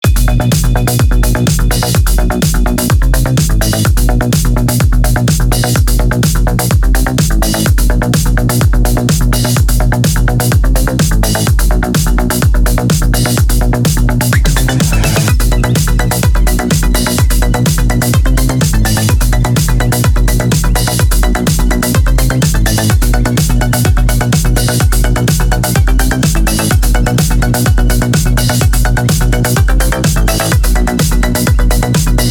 Отличный звук, настойчивый, бодрый трек.